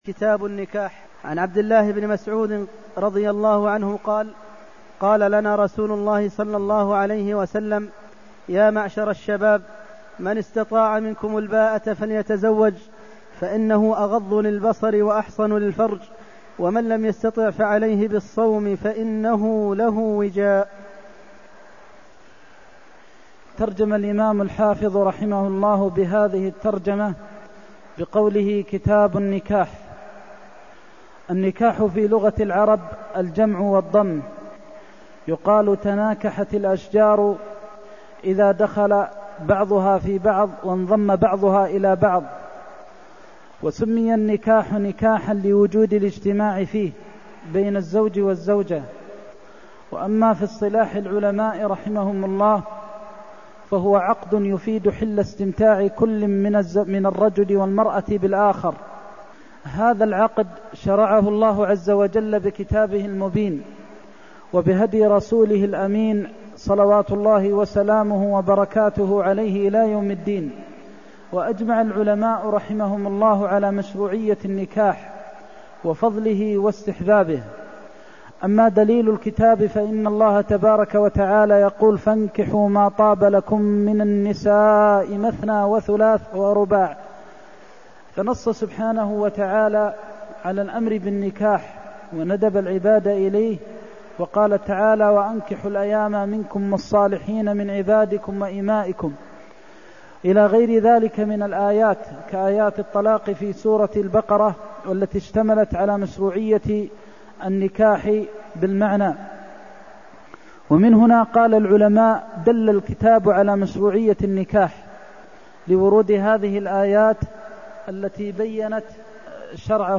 المكان: المسجد النبوي الشيخ: فضيلة الشيخ د. محمد بن محمد المختار فضيلة الشيخ د. محمد بن محمد المختار من استطاع منكم الباءة فليتزوج (284)